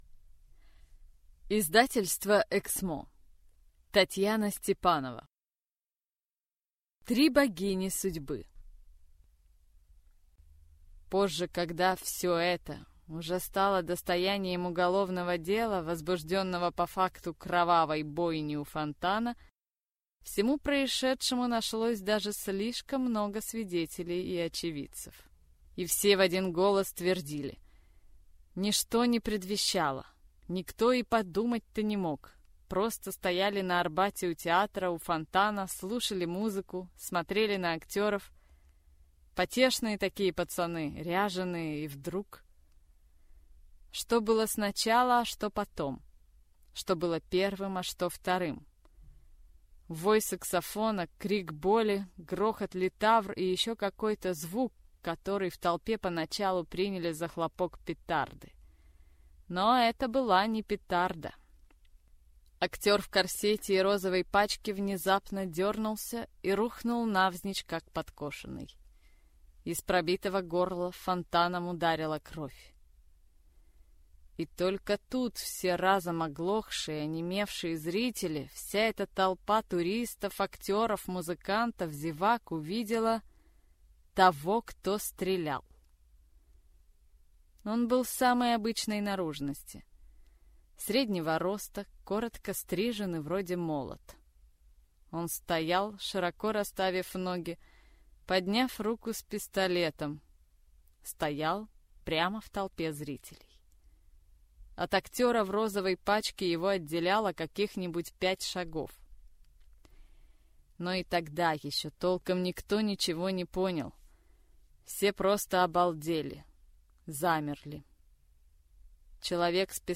Аудиокнига Три богини судьбы | Библиотека аудиокниг
Прослушать и бесплатно скачать фрагмент аудиокниги